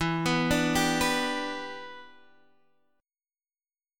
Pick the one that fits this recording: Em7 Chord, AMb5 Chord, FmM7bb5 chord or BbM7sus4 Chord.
Em7 Chord